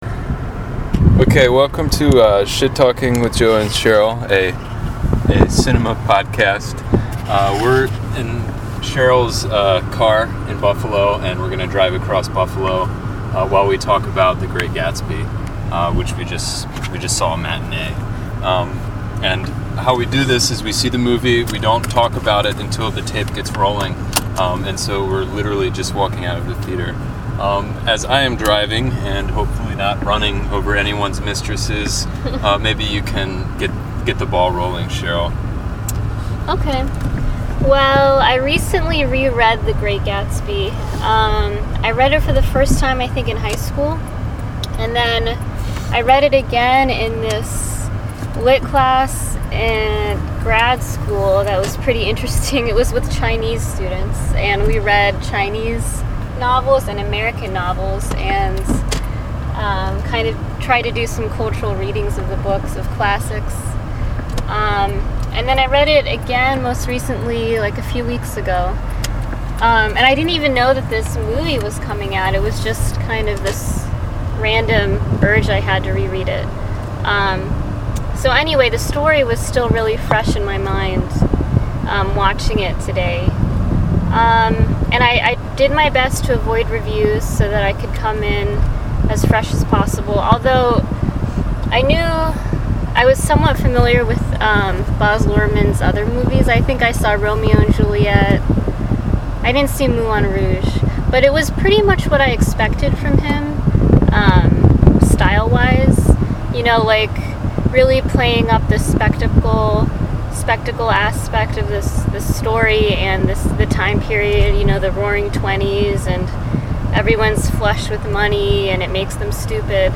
As usual, we didn’t talk about the movie at all until we turned the tape recorder on. Here is what we had to say while driving across Buffalo: